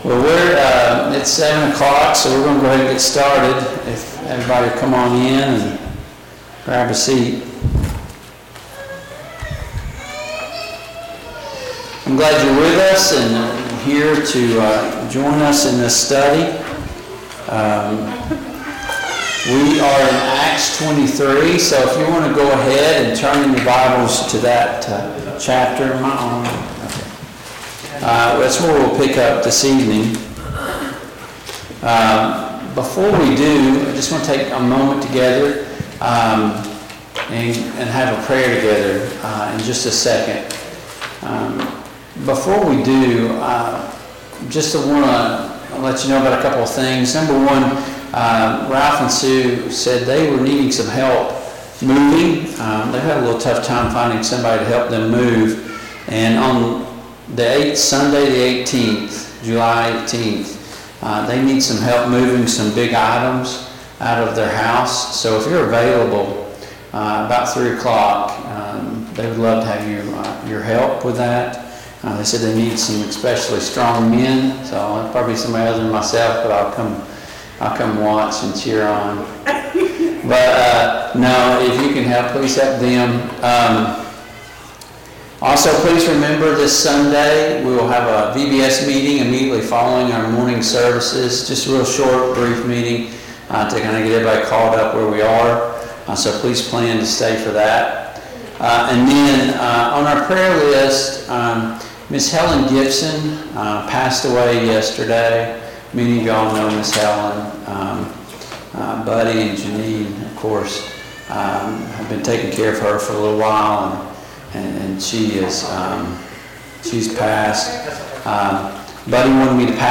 Passage: Acts 23:1-11 Service Type: Mid-Week Bible Study